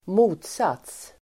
Uttal: [²m'o:tsat:s]